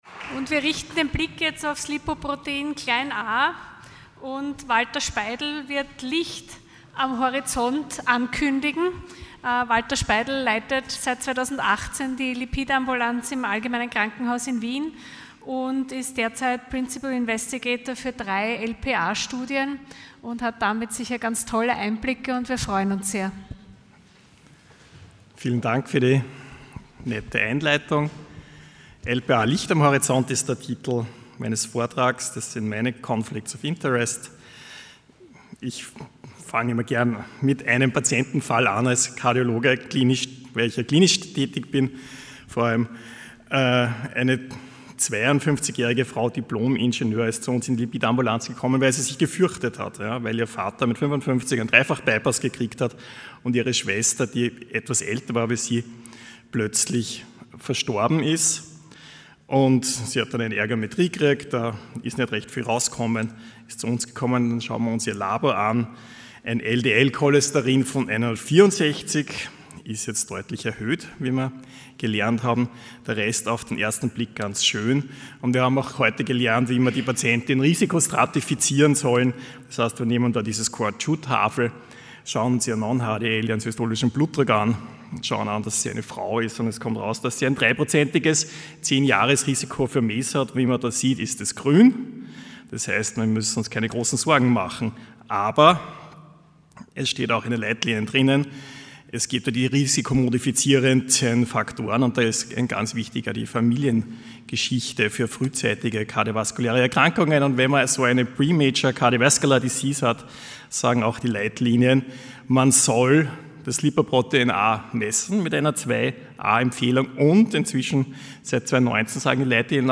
Sie haben den Vortrag noch nicht angesehen oder den Test negativ beendet.
Hybridveranstaltung | Lange Nacht der Lipide in Kooperation mit der Cholesterinallianz Loading the player... 0